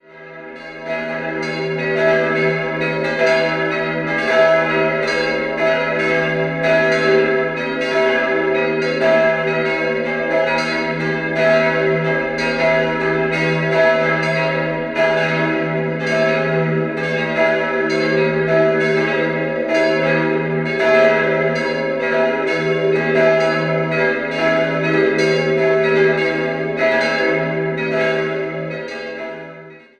4-stimmiges Geläut: e'-gis'-h'-cis'' Die kleine und die große Glocke wurden 1952 von Perner in Passau gegossen, die zweitgrößte stammt aus dem Jahr 1723, die dritte von 1521.